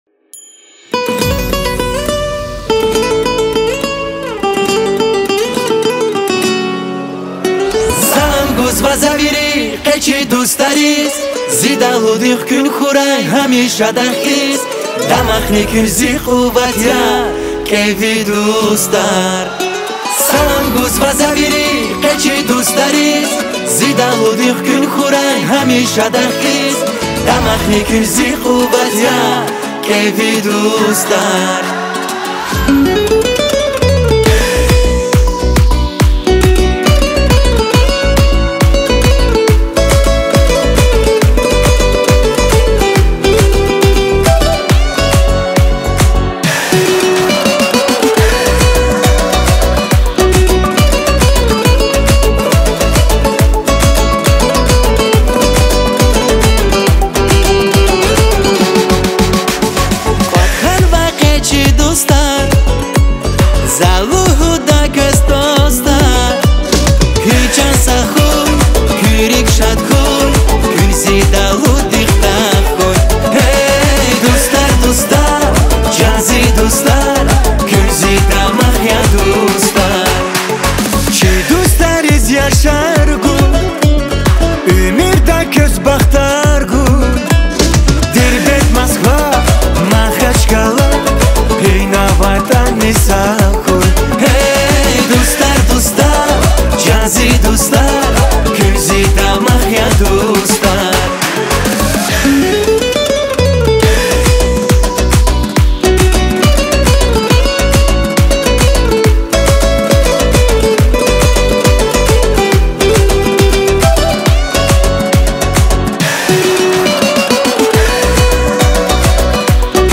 • Качество: 320 kbps, Stereo
лезгинская песня